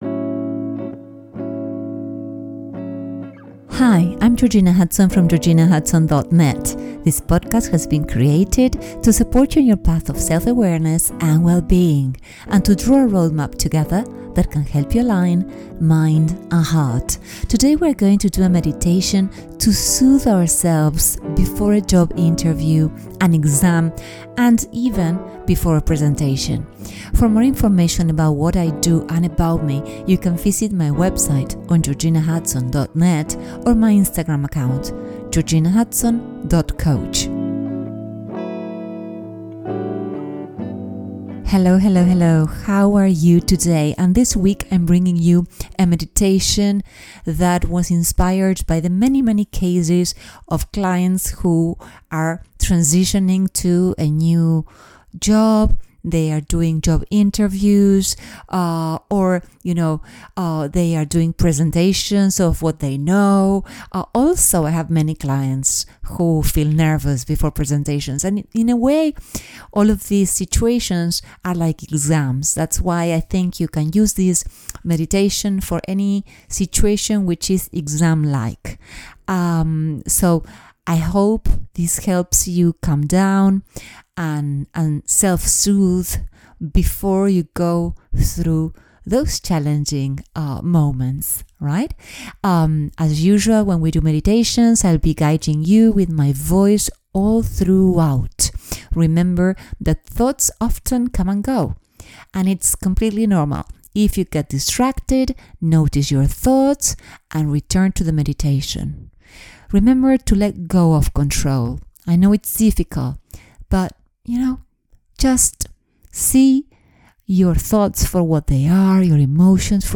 Meditation to calm yourself before a job interview, exam, or presentation
This week I’m bringing you something very special: a guided meditation specifically designed to help you calm yourself before a job interview, exam, and /or presentation.